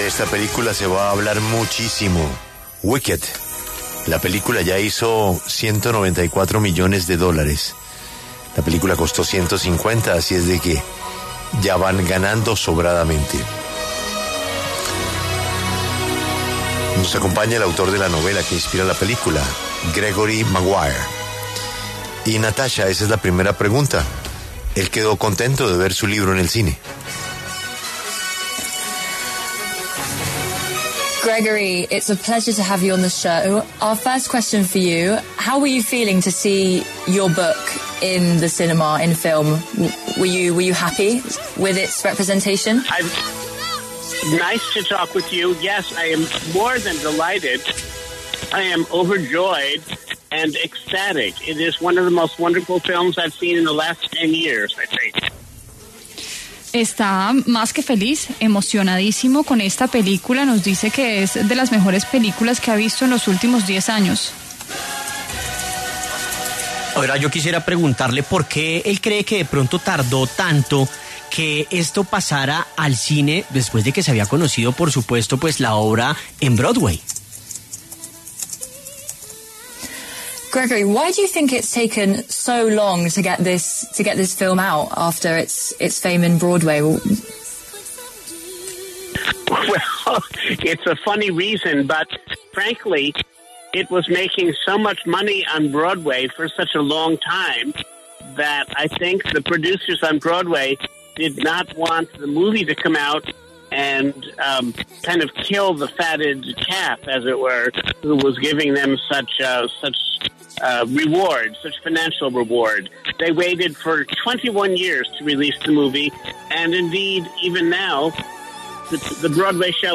Por esta razón, Gregory Maguire, novelista estadounidense y autor de ‘Wicked’, habló en La W, con Julio Sánchez Cristo, sobre la adaptación al cine de su obra.